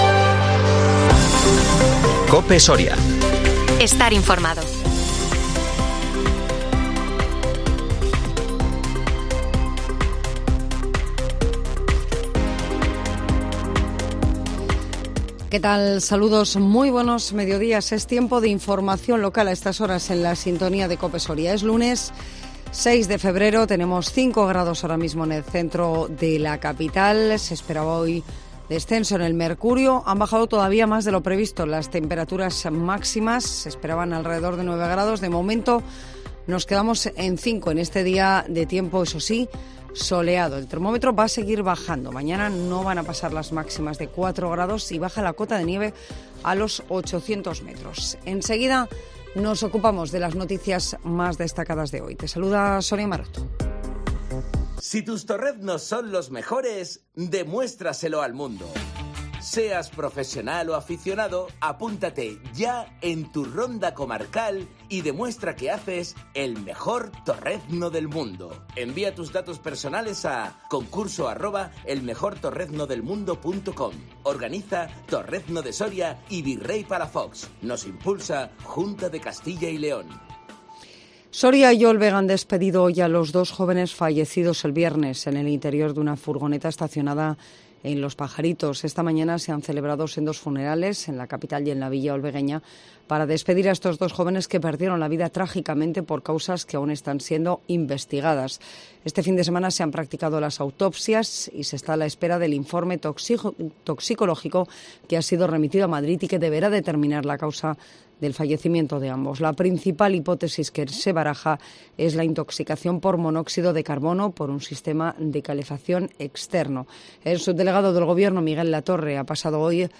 INFORMATIVO MEDIODÍA COPE SORIA 6 FEBRERO 2023